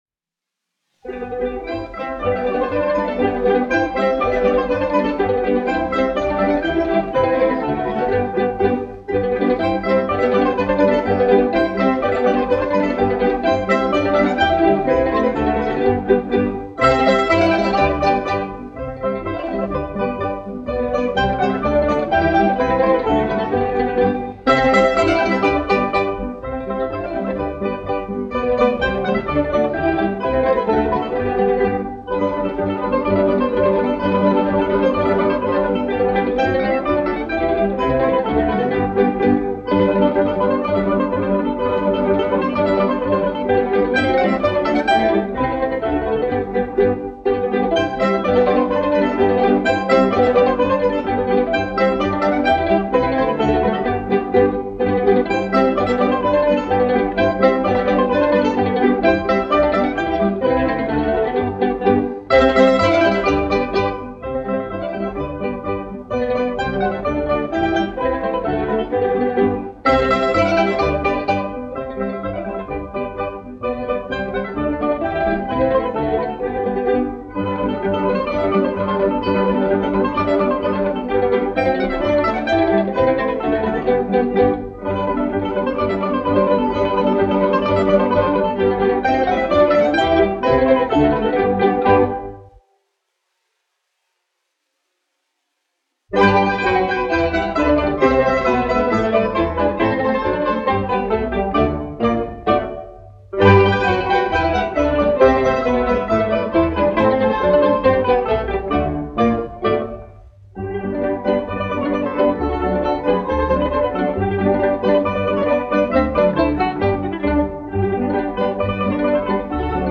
Ritenitis ; Sudmalinyas : Lettisch folk dances
1 skpl. : analogs, 78 apgr/min, mono ; 25 cm
Latviešu tautas dejas
Tautas mūzika -- Latvija
Skaņuplate